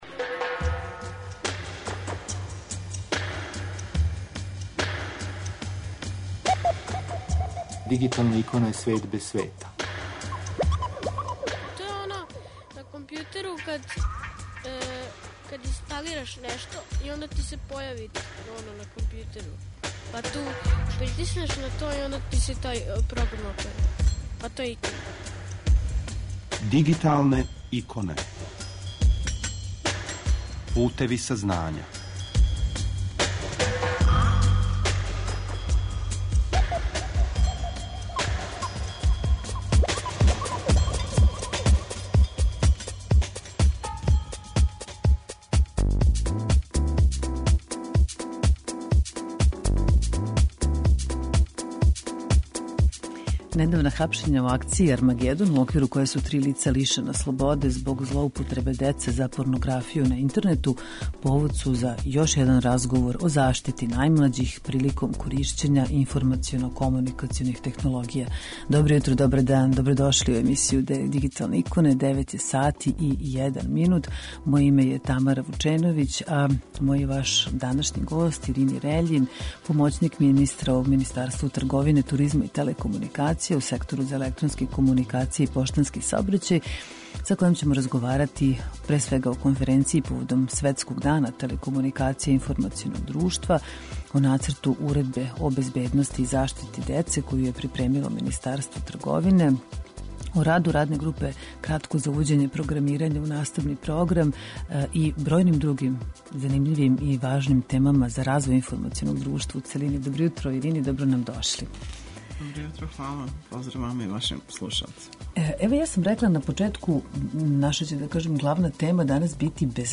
Са нама уживо Ирини Рељин, помоћник министра у Министарству трговине, туризма и телекомуникација (Сектор за електронске комуникације и поштански саобраћај) са којом разговарамо о Конференцији поводом светског дана телекомуникација и информационог друштва, Стратегији о информационој безбедности, о нацрту Уредбе о безбедности и заштити деце коју је припремило Министарство трговине, туризма и телекомуникација, пројекту ИТ караван, раду Радне групе за увођење програмирања у наставни програм и многим другим актуелним темама као и о новом виртуалном мобилном оператеру Mundio mobile.